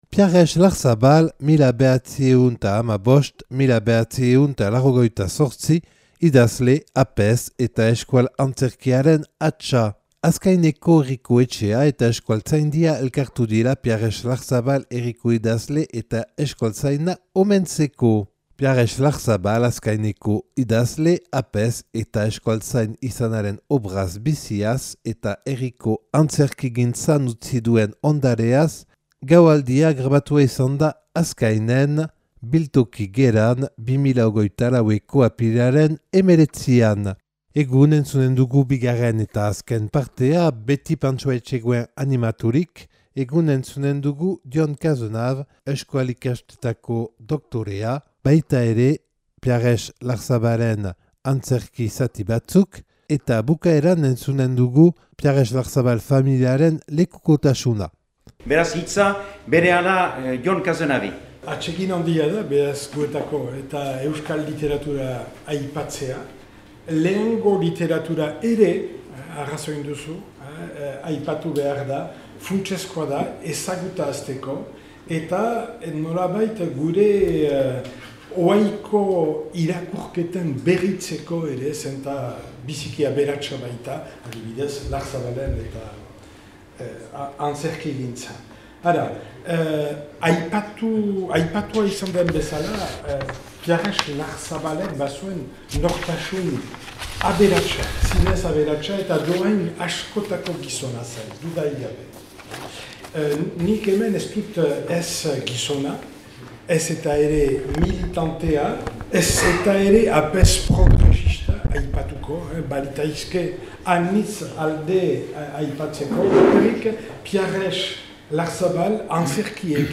(Azkainen grabatua 2024. apirilaren 19an. Euskaltzaindia, Euskal Kultur Erakundea eta Azkainko herriak antolaturik).